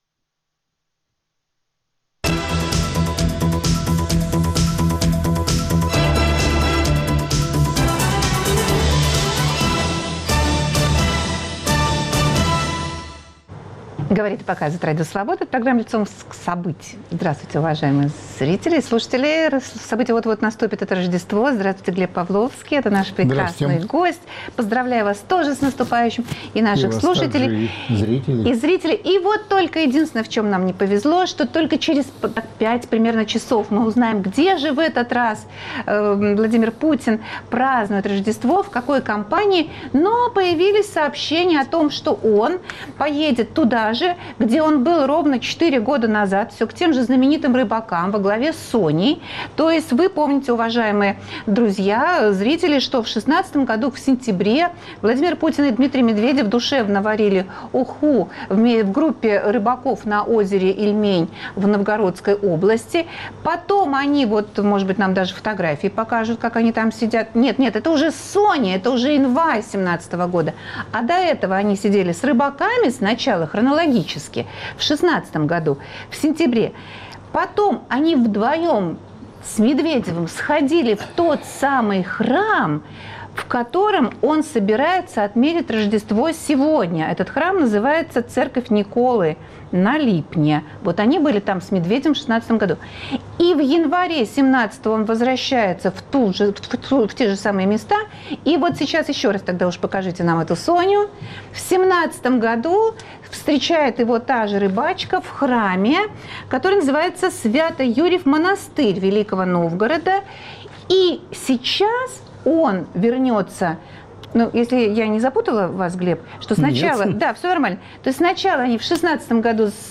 А также: рейтинг провалов кремлевской пропаганды с точки зрения Еврокомиссии. Гость студии - политолог Глеб Павловский.